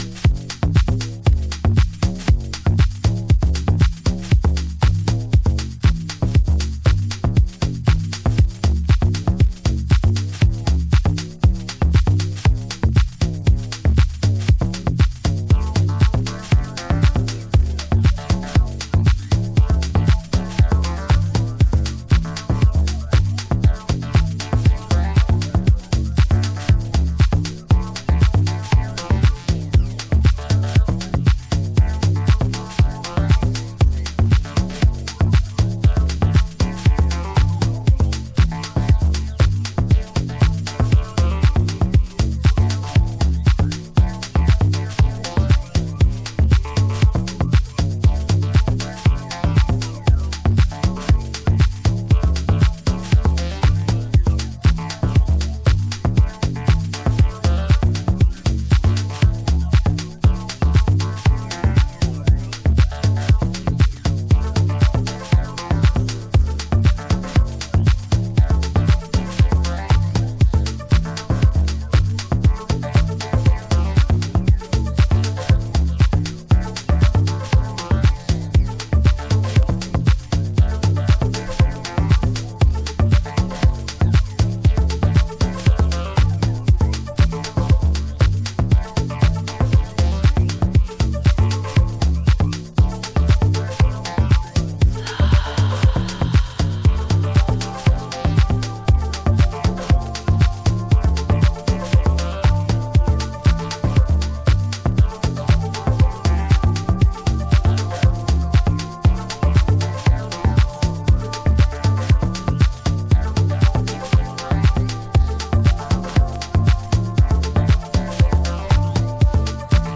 どれか1曲におすすめを絞るのが難しい、良質なハウスEPです。